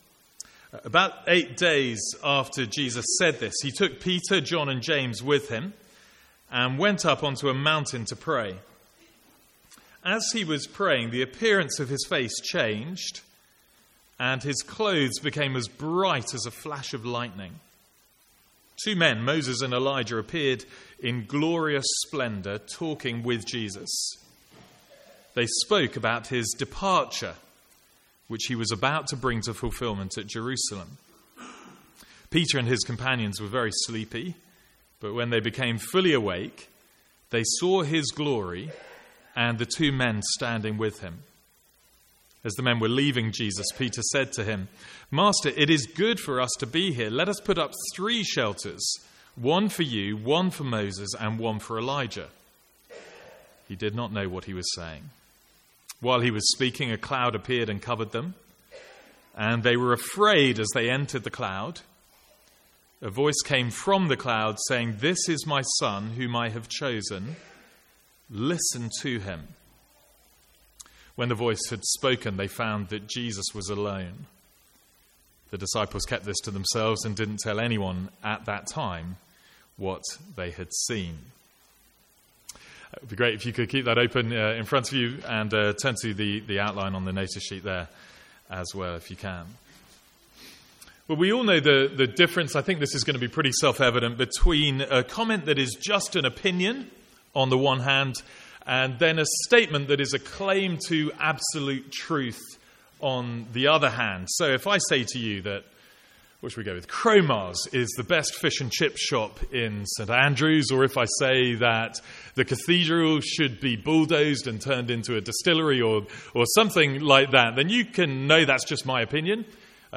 Sermons | St Andrews Free Church
From the Sunday morning series in Luke.